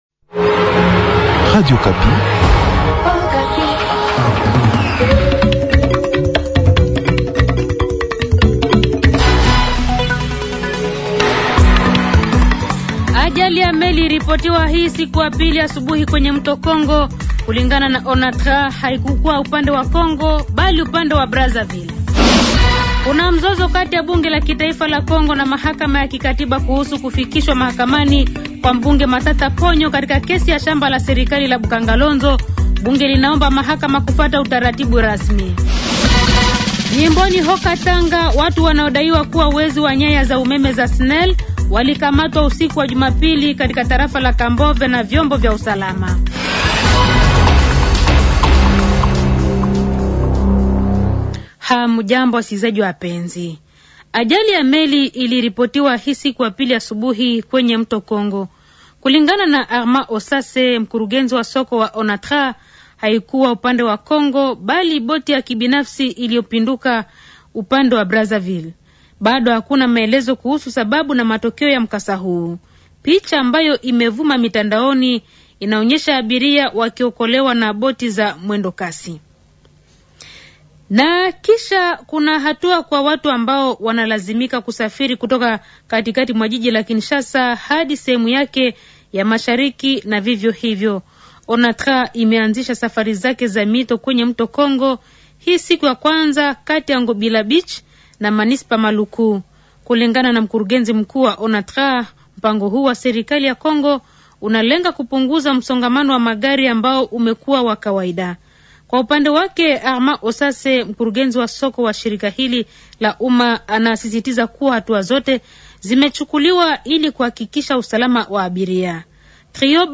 Journal Matin
Habari z'asubuhi 23 Aprili, 2025